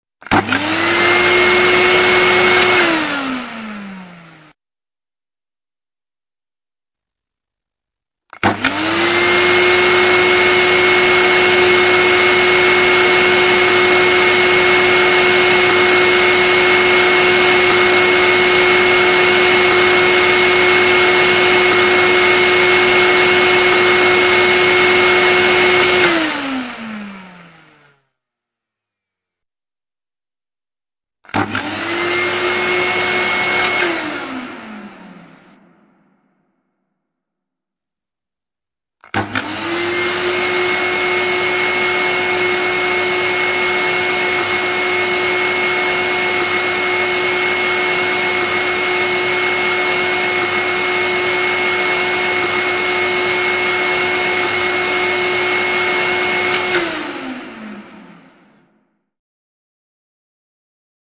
描述：一个真空清洁
Tag: 真空 清洁 胡佛吸尘器